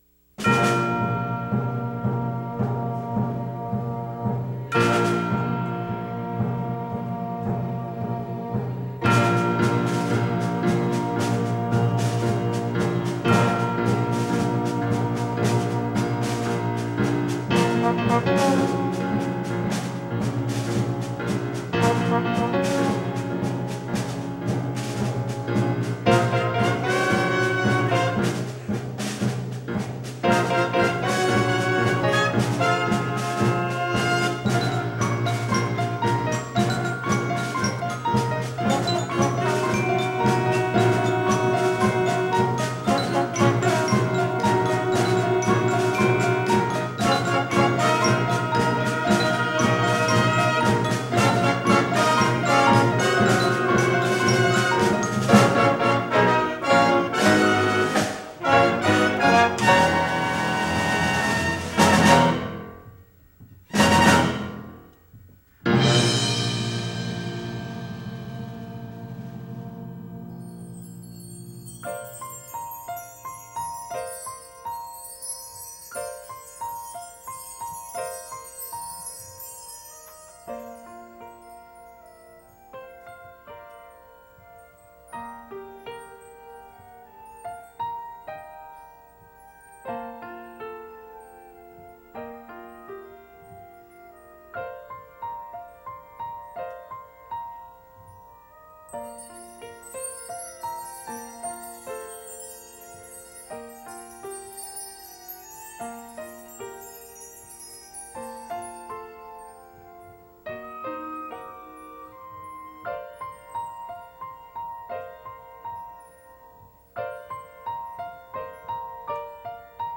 “Old Rugged Cross” – Faith Baptist Church Orchestra
old-rugged-cross-orchestra.mp3